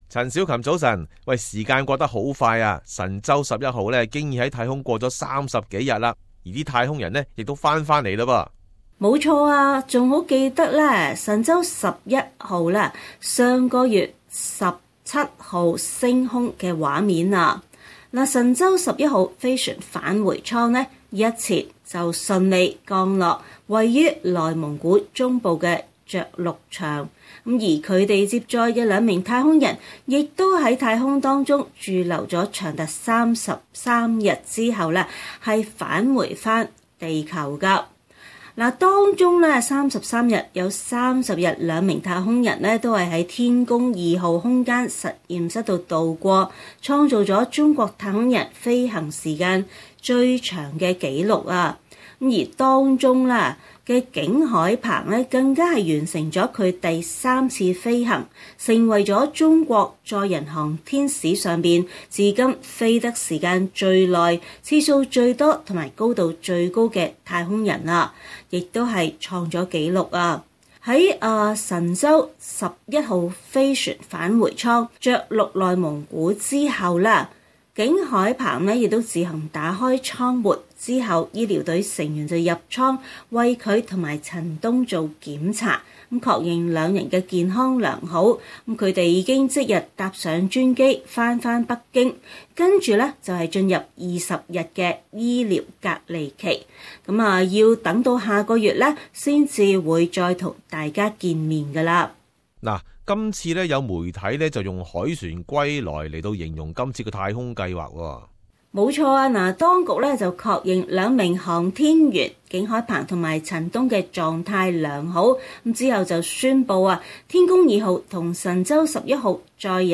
Source: AAP SBS廣東話節目 View Podcast Series Follow and Subscribe Apple Podcasts YouTube Spotify Download (2.75MB) Download the SBS Audio app Available on iOS and Android 中國太空船神舟十一號的太空人安全返回地球，創出中國太空人逗留在太空最長的時間。